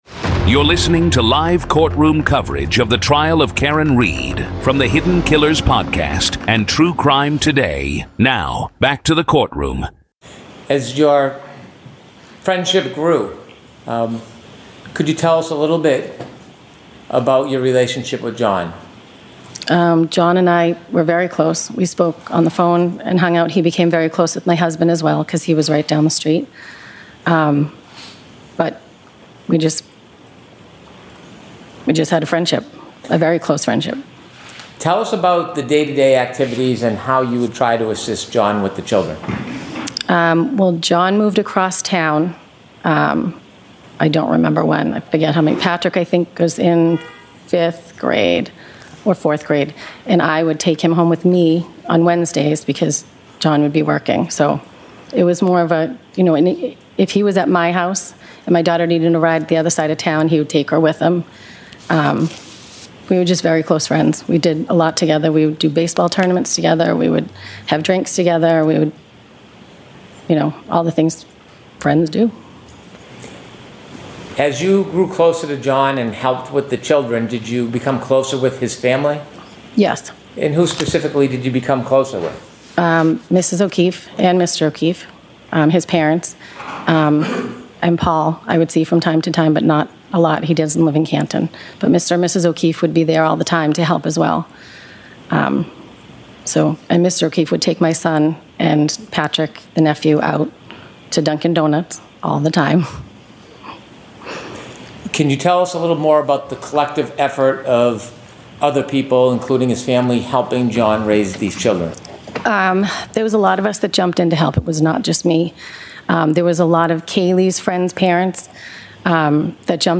This is live audio from the courtroom in the high-profile murder retrial of Karen Read in Dedham, Massachusetts.